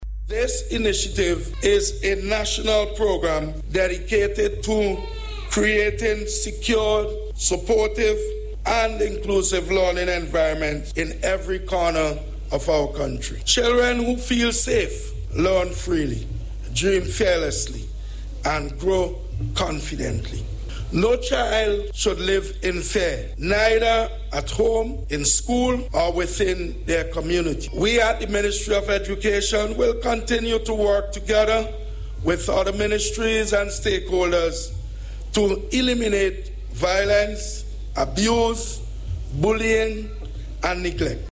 That’s according to Minister of Education, Curtis King, who made the declaration while addressing the launch of child month activities.